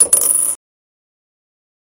フリー効果音：コイン1
フリー効果音｜ジャンル：かんきょう、コインの音１つ目！ゲームセンターなどのシーンとかにぴったり！
coin1.mp3